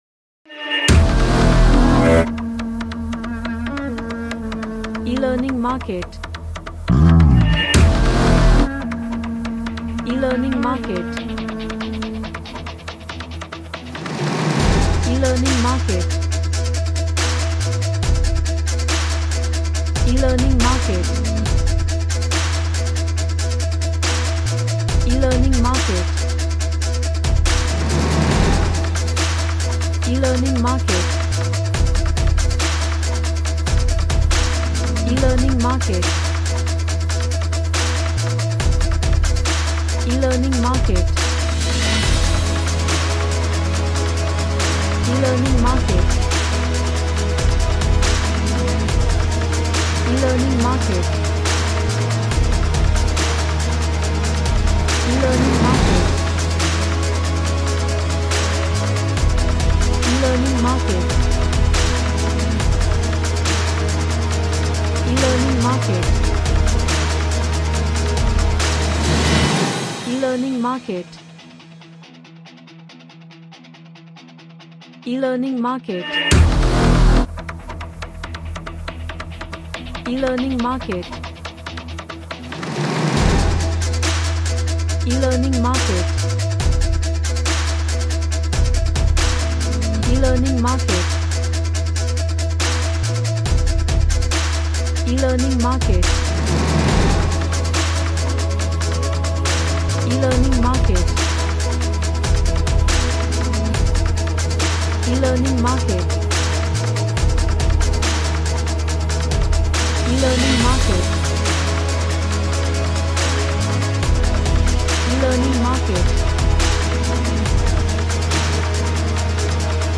Epic Hard action theme music with cinematic punch
Action / Sports